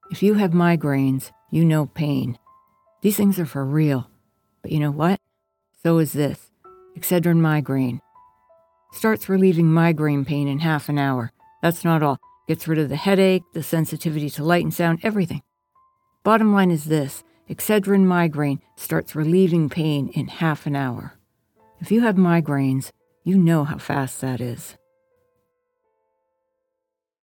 Female
English (North American)
Adult (30-50), Older Sound (50+)
Radio / TV Imaging
Migraine, Real, Grounded